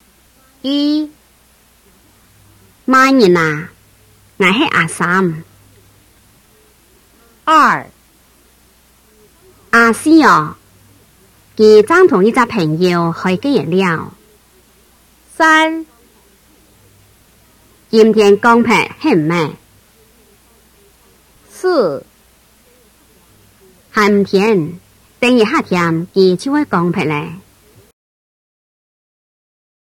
In each of the audio files below, the speaker will say the following, at least how they would say the same thing in their dialect.
5. Méixiàn Dialect (Hakka Group; Guangdong Province)
05-meixian-hua.m4a